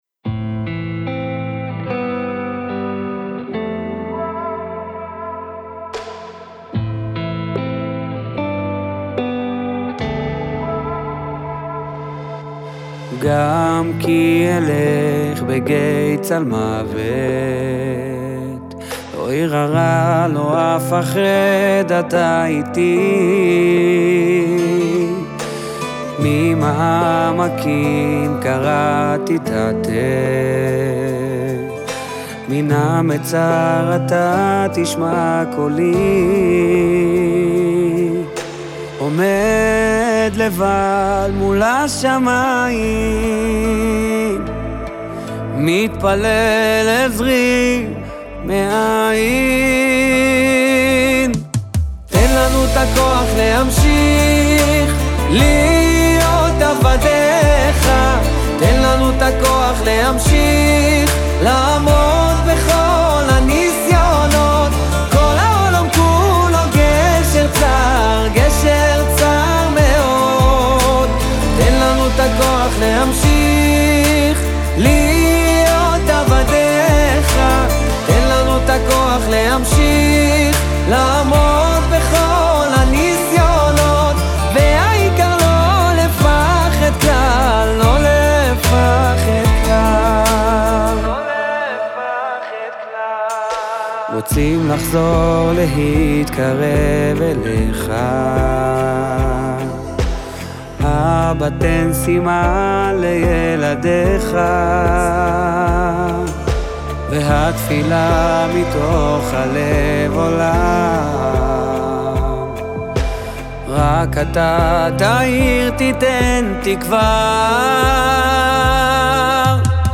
שיר נוגע ללב
מעביר תחושה של געגוע וחיפוש פנימי.